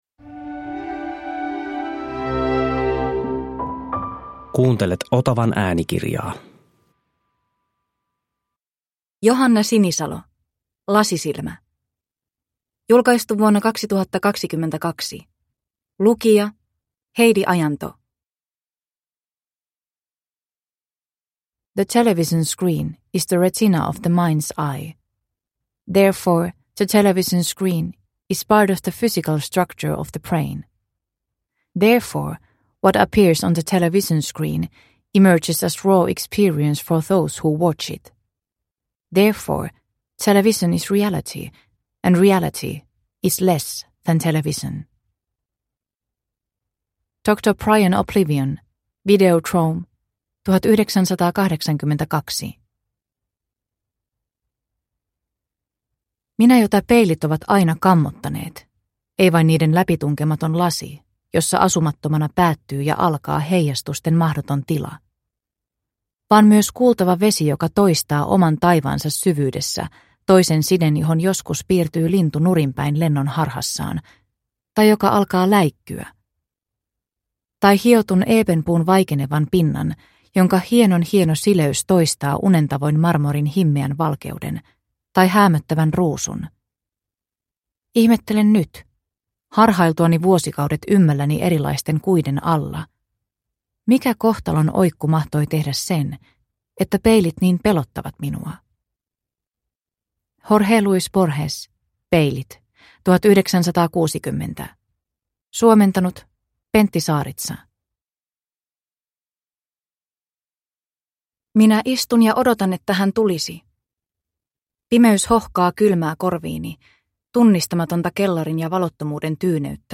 Lasisilmä – Ljudbok – Laddas ner